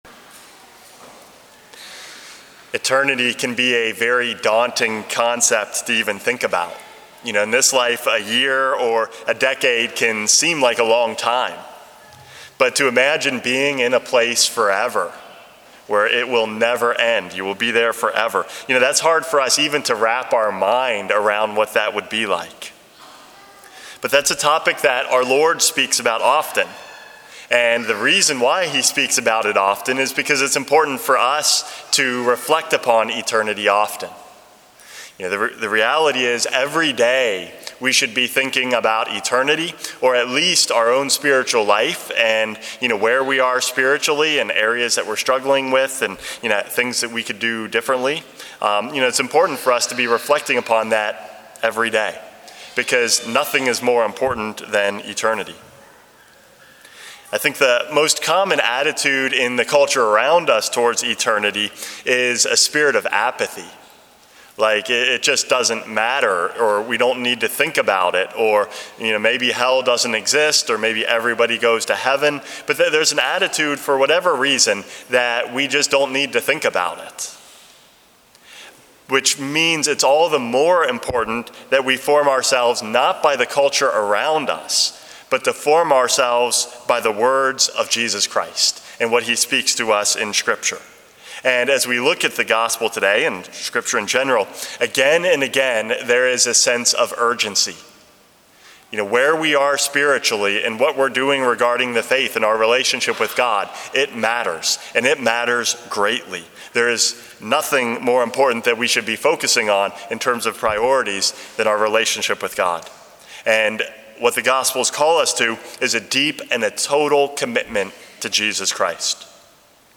Homily #416 - Looking to Eternity